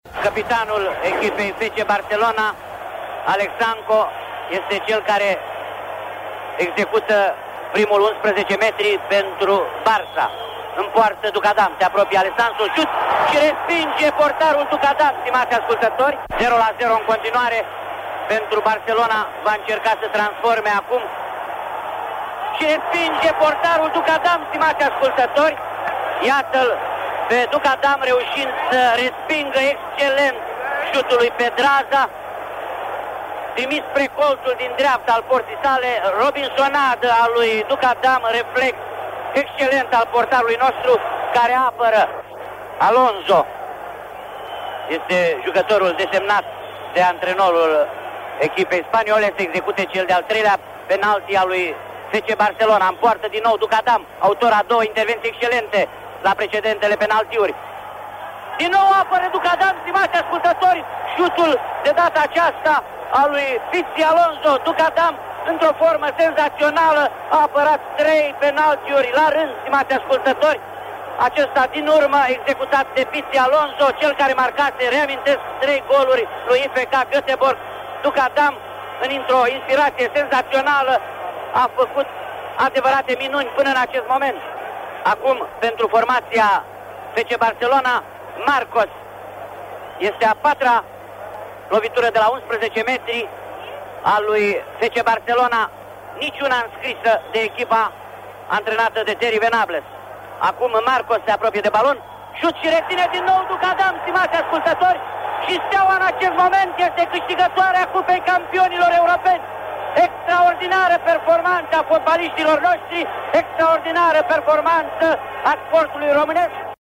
O voce caldă și totodată impunătoare, un om care își găsea întotdeauna timp să stea de vorbă cu tine.